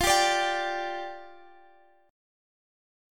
G5/F chord